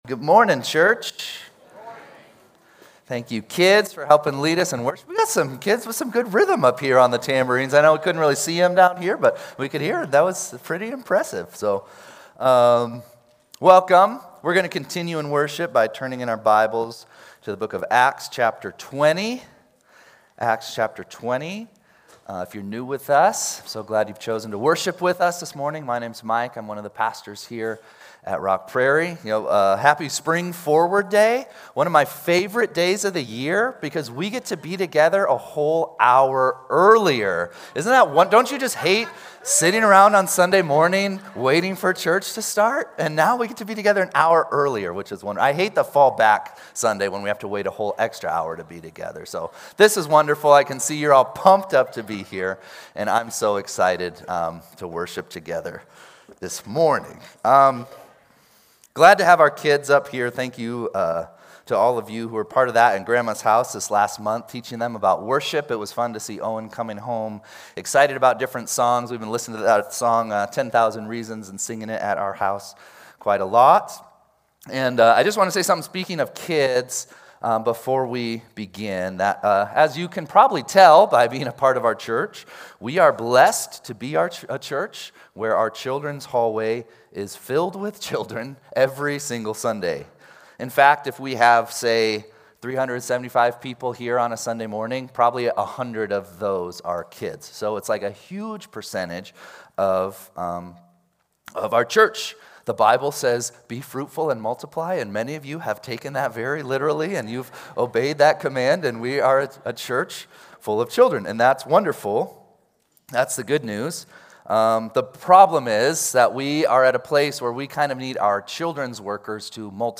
3-8-26-Sunday-Service.mp3